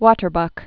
(wôtər-bŭk, wŏtər-)